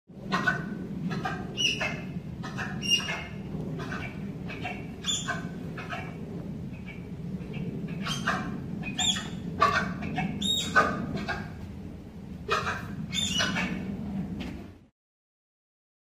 На этой странице собраны разнообразные звуки тапиров — от нежного похрюкивания до громкого рычания.
Звук тапіра